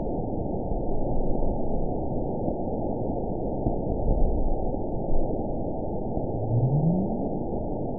event 920108 date 02/23/24 time 00:41:09 GMT (1 year, 3 months ago) score 9.69 location TSS-AB01 detected by nrw target species NRW annotations +NRW Spectrogram: Frequency (kHz) vs. Time (s) audio not available .wav